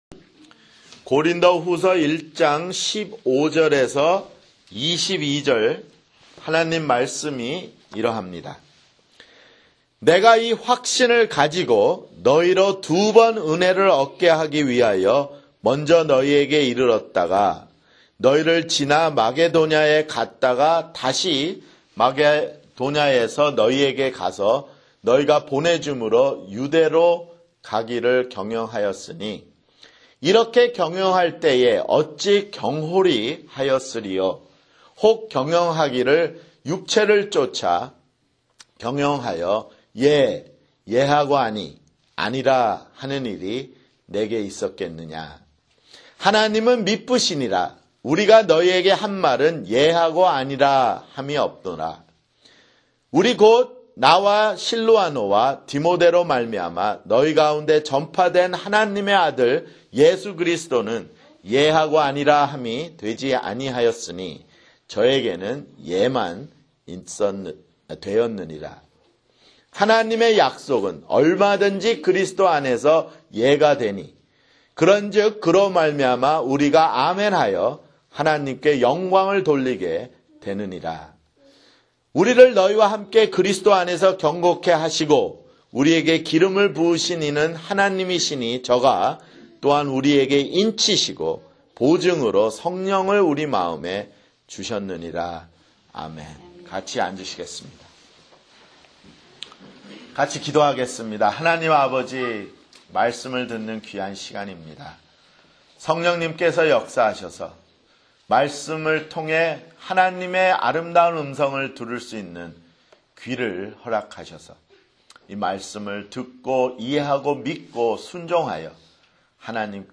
[주일설교] 고린도후서 (11)